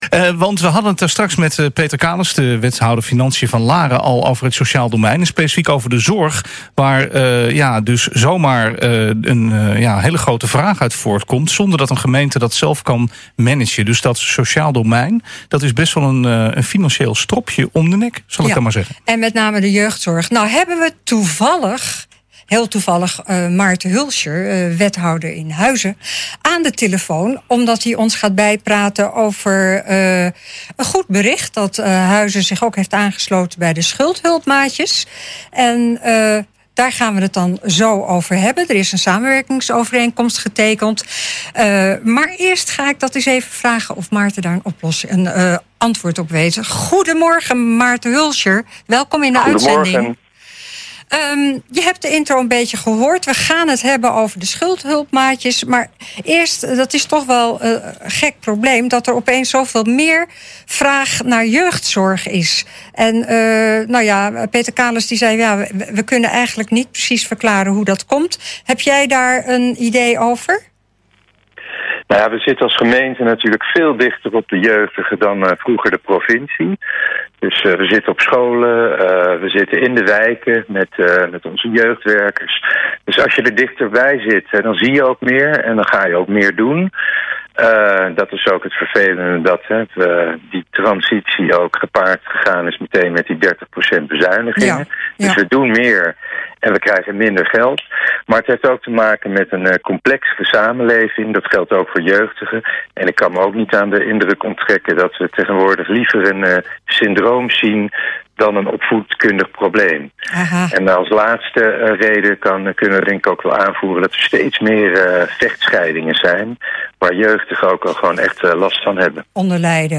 Het werk van Schuldhulp Huizen is al een aantal jaren gaande en werd deze week verder geprofessionaliseerd door de ondertekening van een samenwerkingsovereenkomst met Schuldhulpmaatje Nederland. Aan de telefoon verantwoordelijk wethouder is Maarten Hoelscher.